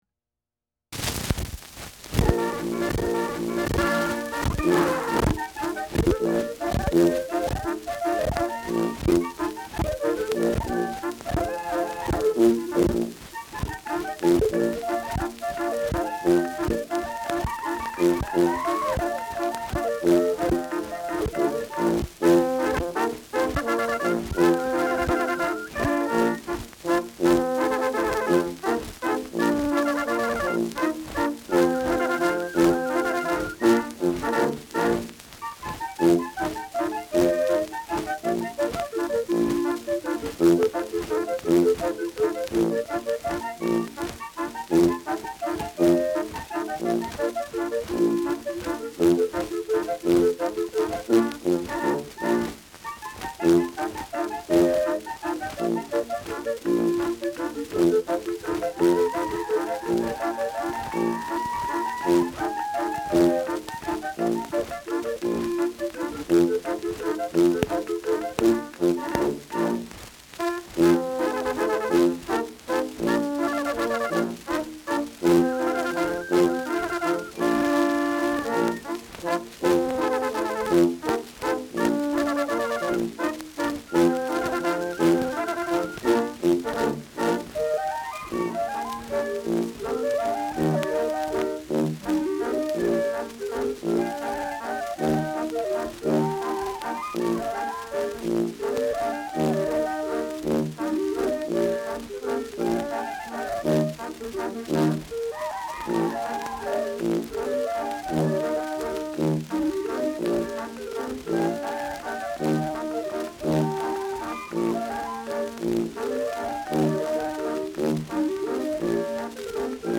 Schellackplatte
fragmentarisch digitalisiert : „Hängen“ am Anfang : präsentes Rauschen
Thüringer Ländler-Kapelle (Interpretation)